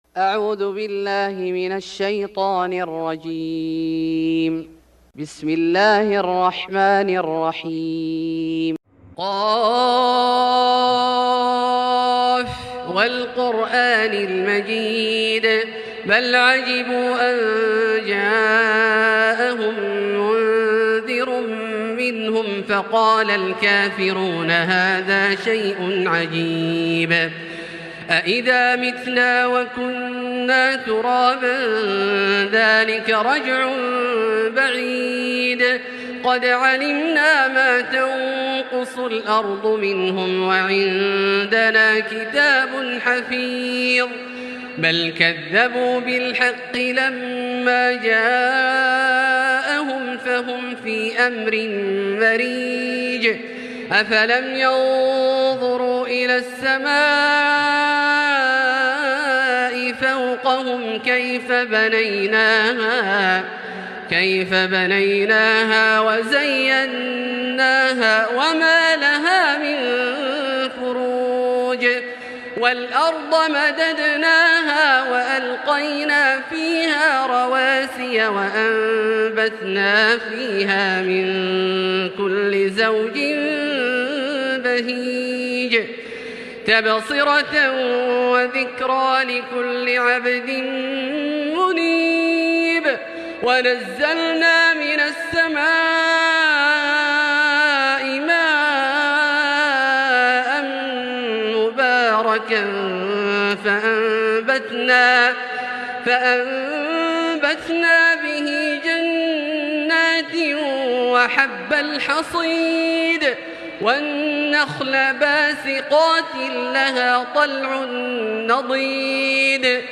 سورة ق Surat Qaf > مصحف الشيخ عبدالله الجهني من الحرم المكي > المصحف - تلاوات الحرمين